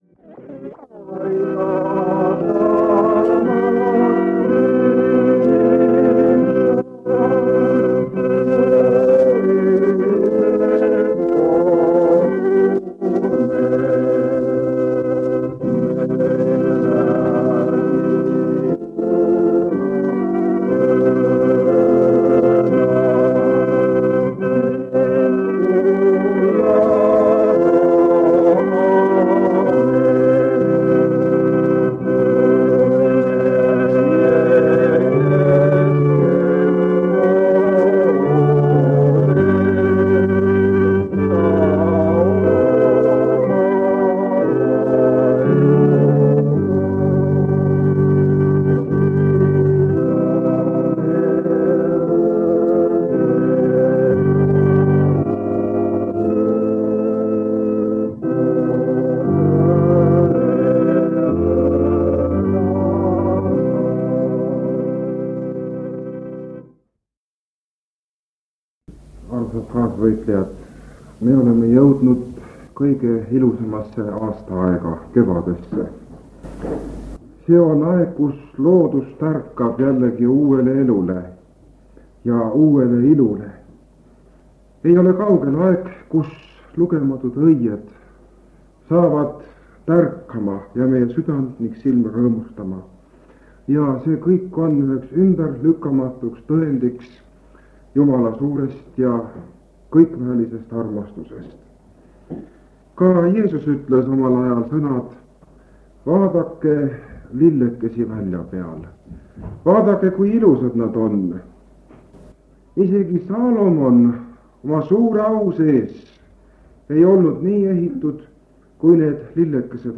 Koosolekute helisalvestused
On 1977 aasta kevad. Paide adventkirikus toimub
Täpsemaid kuupäevi pole teada ning jagasin lintmaki lintidele talletatu kuueks päevaks.